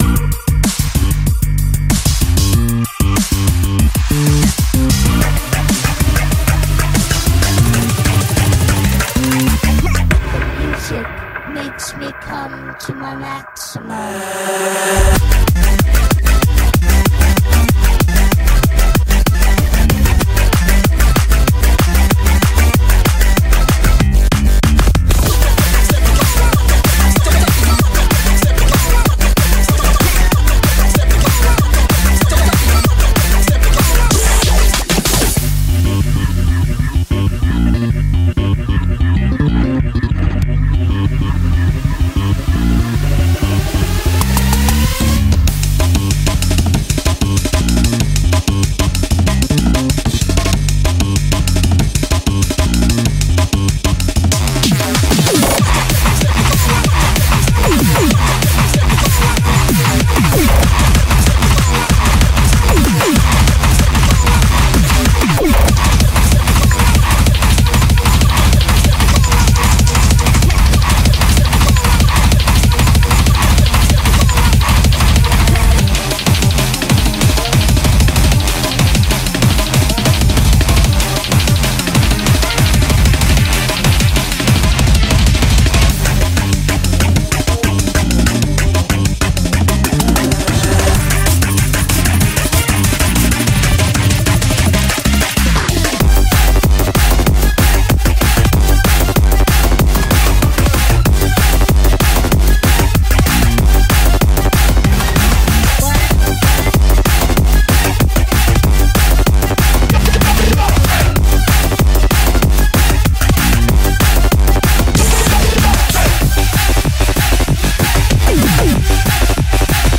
BPM190
Audio QualityMusic Cut
GENRE: FUNK RAVE